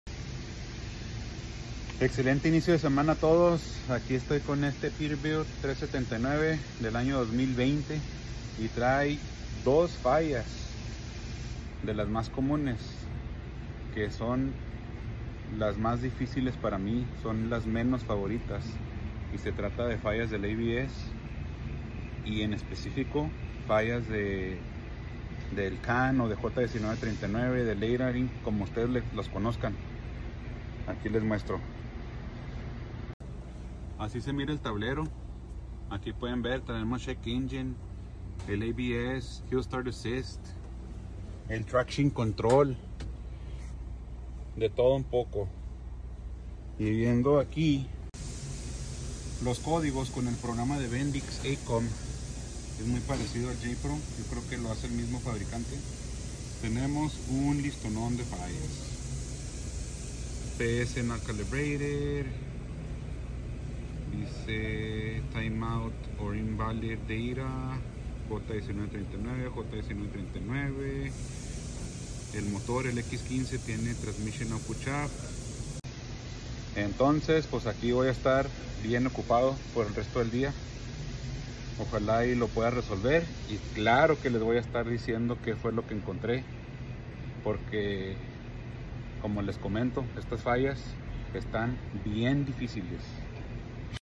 Peterbilt 579 ABS Traction Control